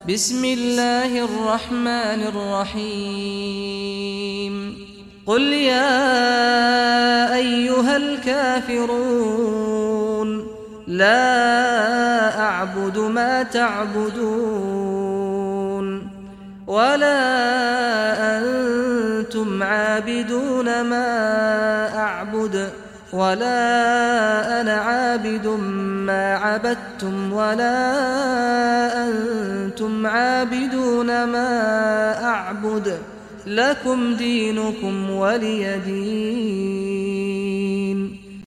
Surah Al-Kafirun Recitation by Sheikh Saad Ghamdi
Surah Al-Kafirun, listen or play online mp3 tilawat / recitation in Arabic in the beautiful voice of Sheikh Saad al Ghamdi.